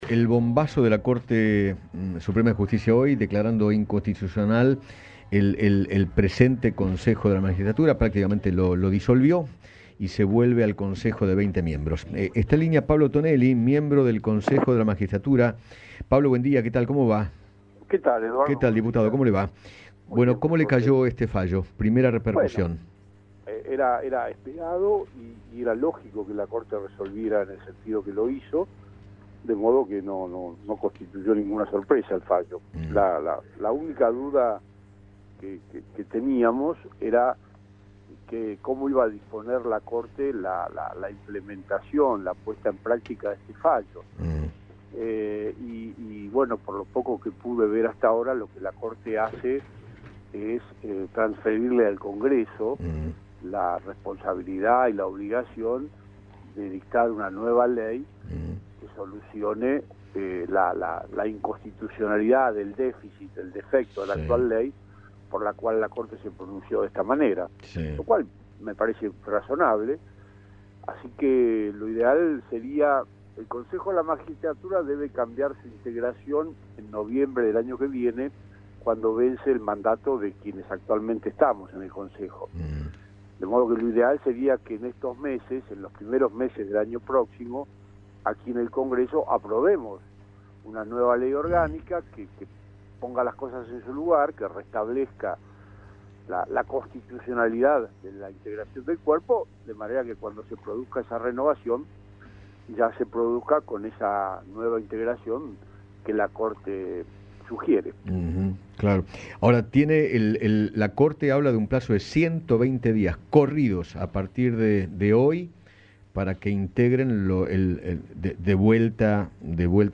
Pablo Tonelli, miembro del Consejo de la Magistratura, dialogó con Eduardo Feinmann sobre la decisión de la Corte Suprema de Justicia de invalidar la ley impulsada por Cristina Kirchner, que redujo la cantidad de miembros del Consejo de la Magistratura de 20 a 13, en 2006.